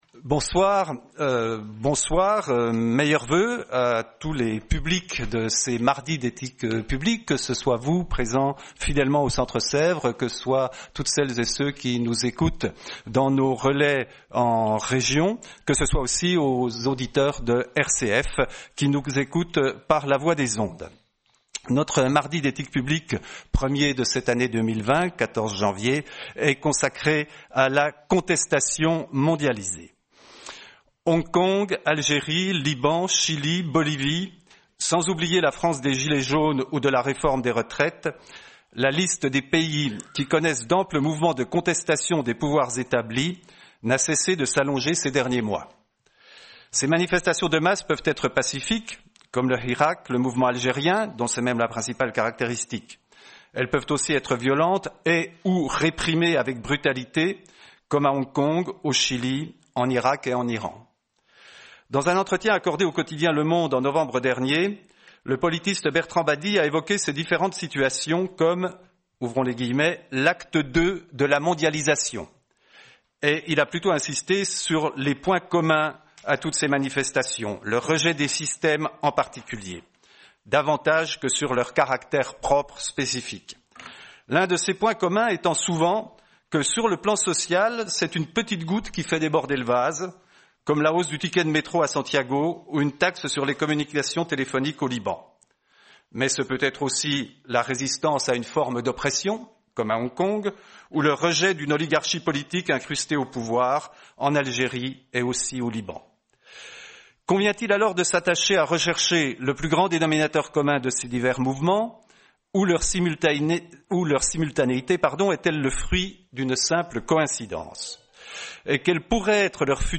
Soirée Mardi d’Ethique publique du 14 janvier 2020, en partenariat avec la revue Études et RCF.